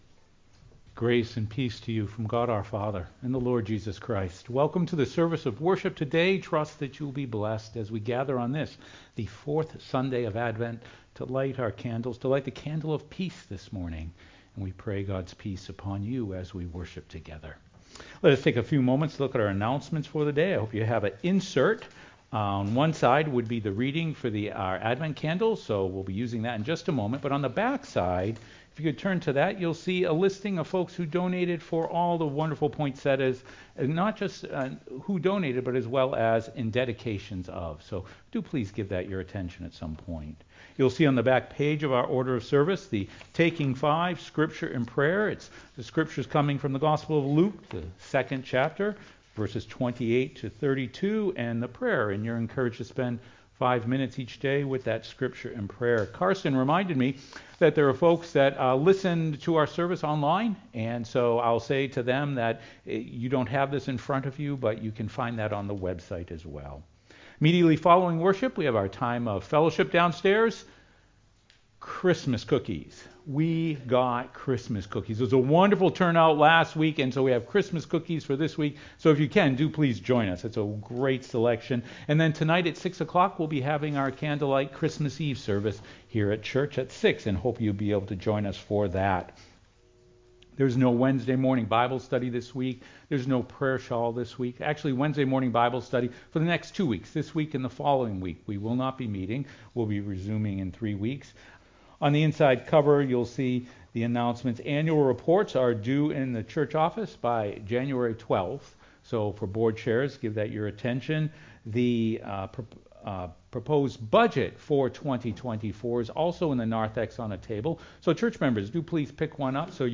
sermon-3.mp3